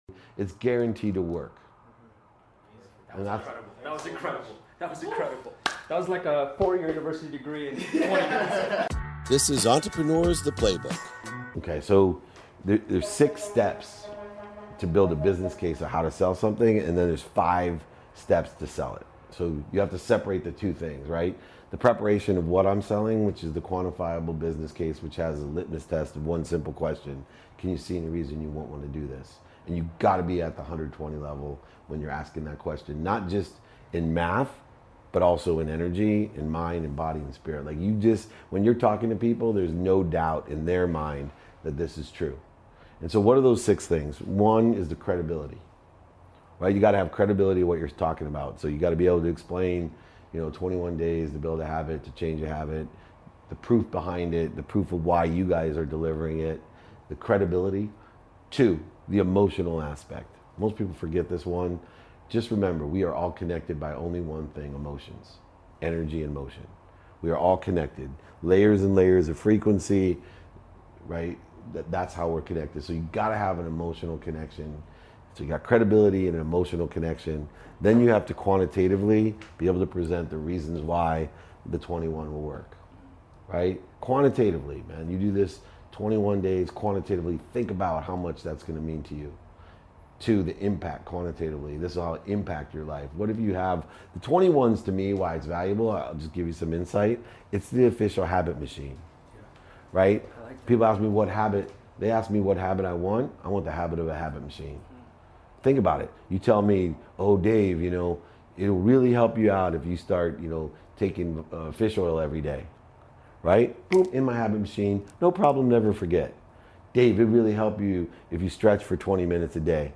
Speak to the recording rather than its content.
Earlier this year I was in Toronto, Canada for Collision Conference and met with 4 young entrepreneurs. I broke down my Five to Thrive sales strategy for them by demonstrating how I would sell them their own product.